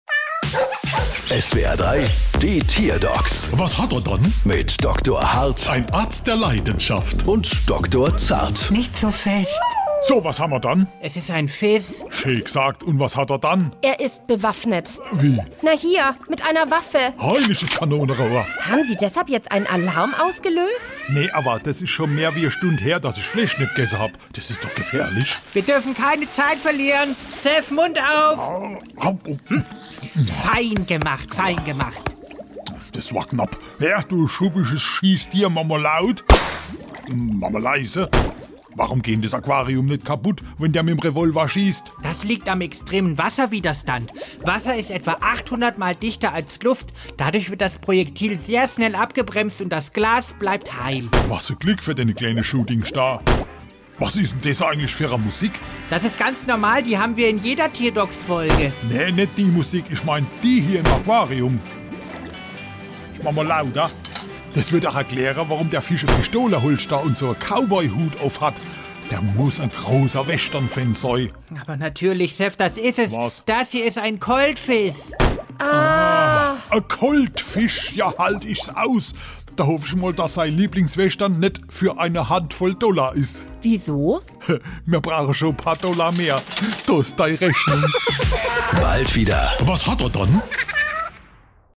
SWR3 Comedy Die Tierdocs: Fisch ist bewaffnet